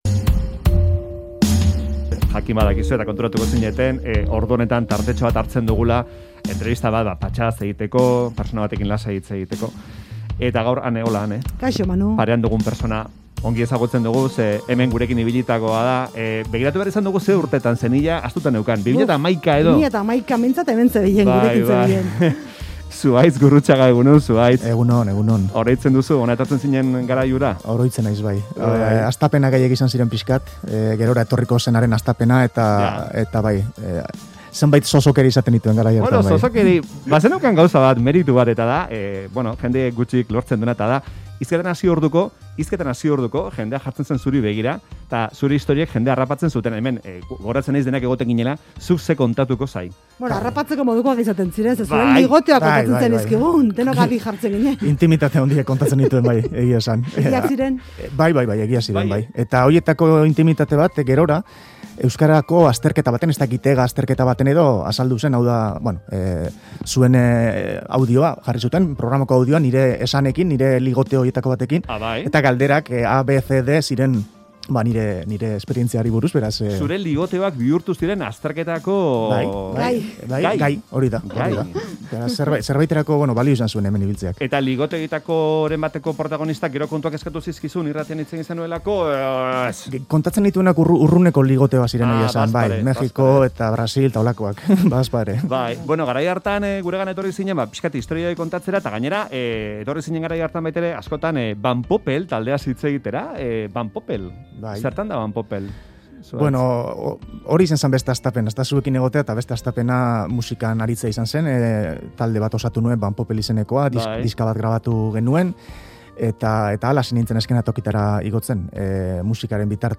Audioa: Zuhaitz Gurrutxagarekin bere bizitzaren eta lanaren errepasoa egin dugu Faktoria saioan.